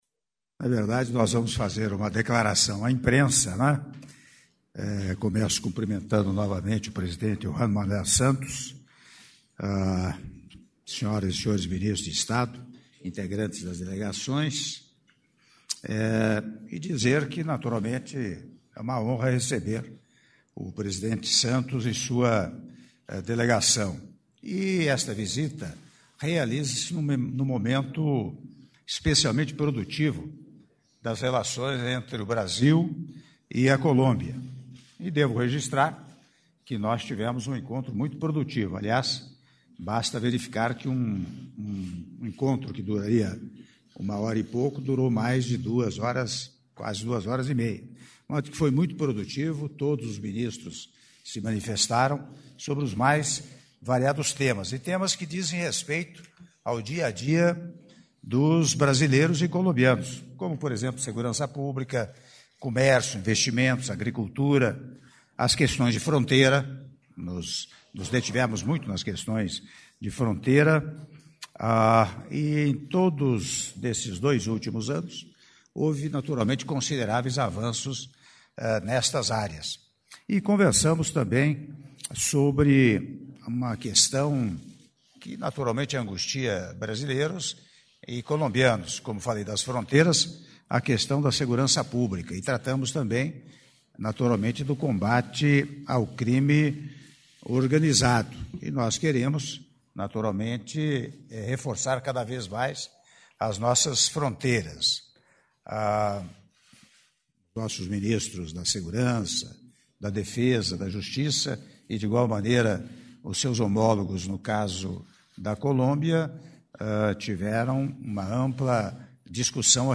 Áudio da declaração à imprensa do Presidente da República, Michel Temer, durante Cerimônia de Assinatura de Atos - (06min58s) - Brasília/DF